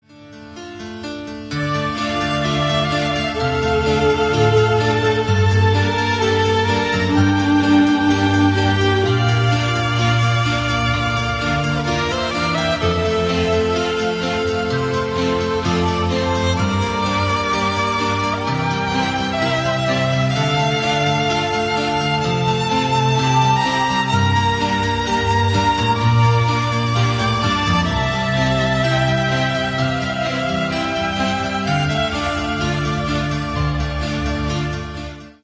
Шансон
грустные
без слов